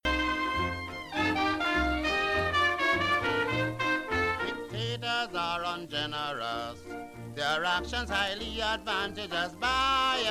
calypson